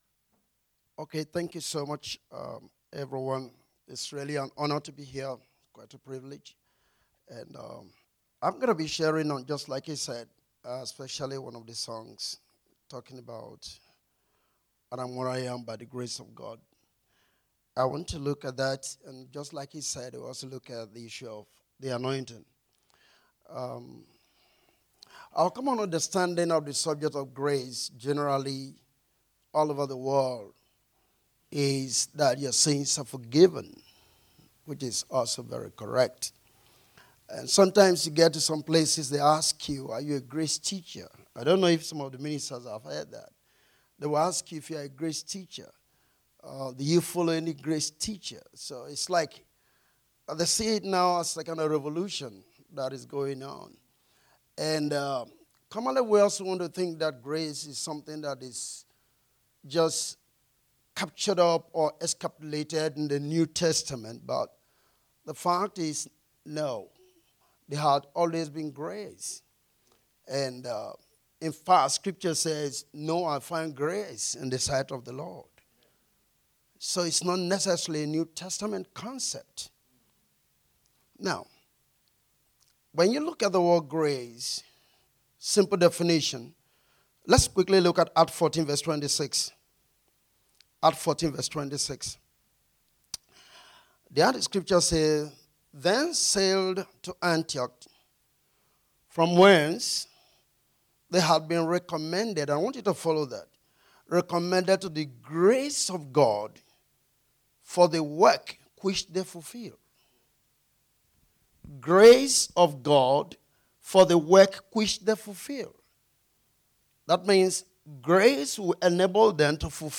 Gate Ministries Durban Central Grace and Anointing Conference19 September 2018